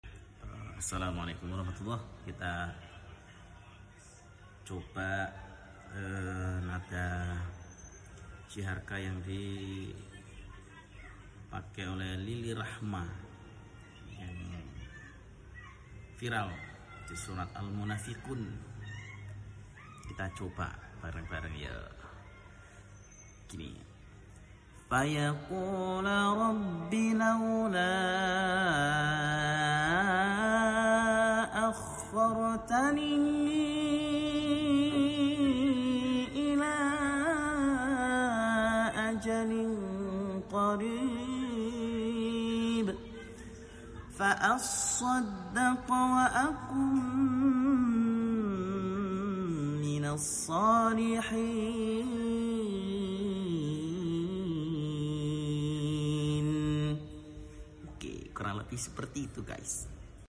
Mencoba Soundtrek yg viral yg mengingatkan kita di jaman jadul dengan nada jiharkah